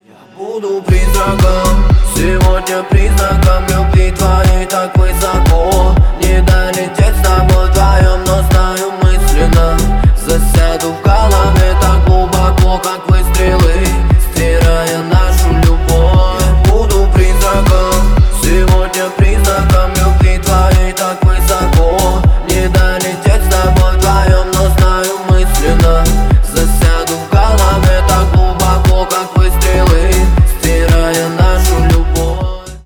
поп
рэп